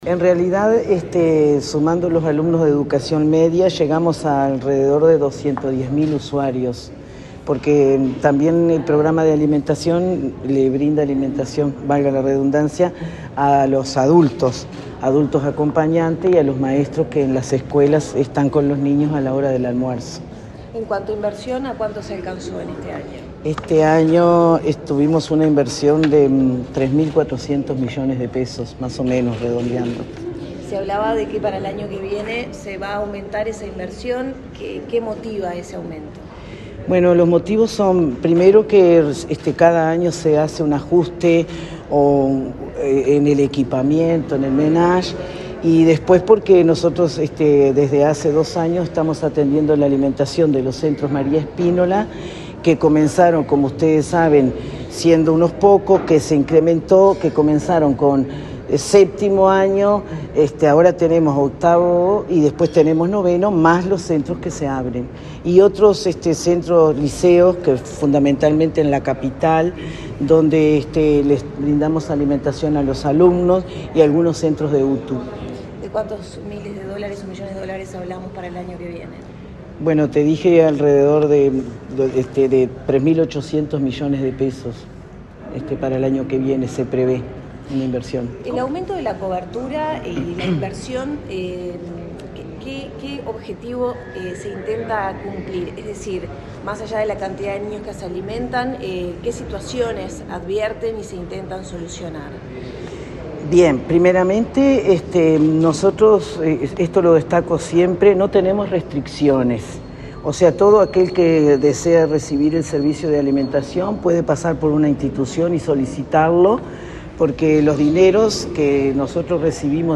Declaraciones de la titular del Programa de Alimentación Escolar de ANEP
La titular del Programa de Alimentación Escolar (PAE), Rosa Lezué, dialogó con la prensa, luego de presentar la rendición de cuentas 2023 de ese plan.